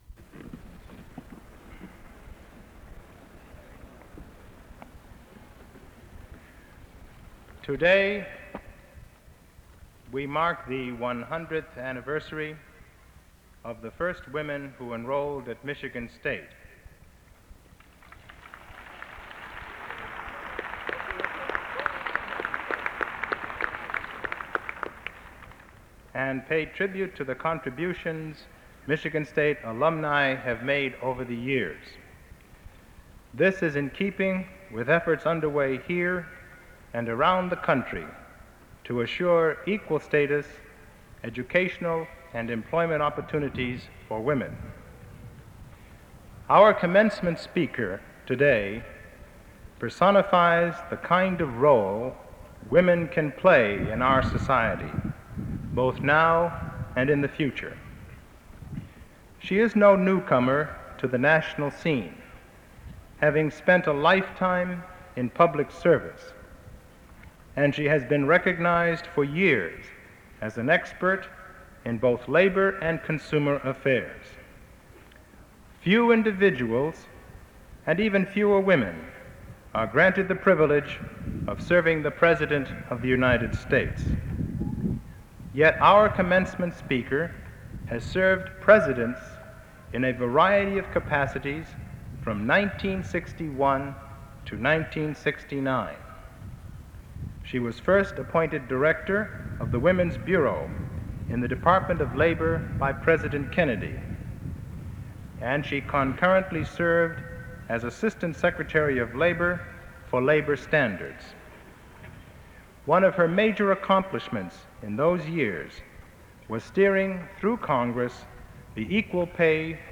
Commencement Address, Spring 1970
A recording of the June 1970 commencement address given by Mrs. Esther Peterson, Legislative Representative for Amalgamated Clothing Workers of America, AFL-CIO. She is introduced by President Clifton R. Wharton, and her address is delivered in Spartan Stadium prior to the conferring of baccalaureate degrees.